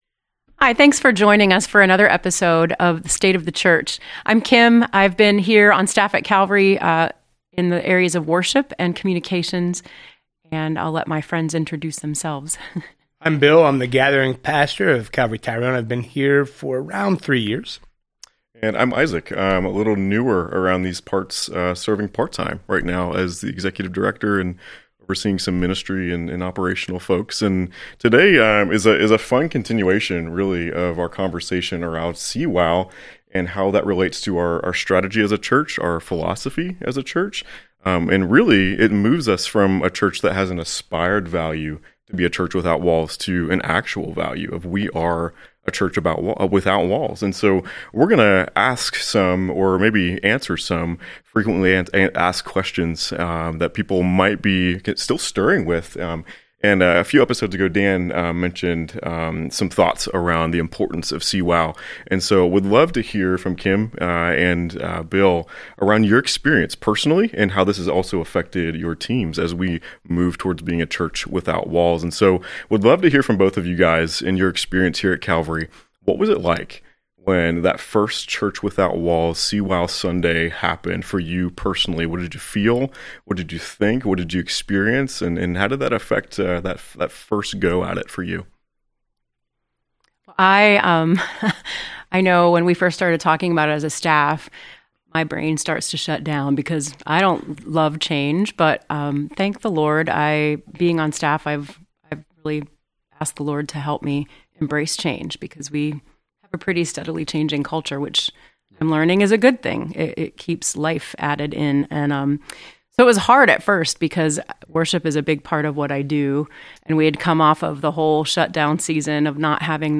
Ep 22. State of the Church | A conversation about your frequently asked questions | Calvary Portal | Calvary Portal